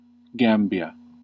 The Gambia (/ˈɡæmbiə/
En-us-Gambia.ogg.mp3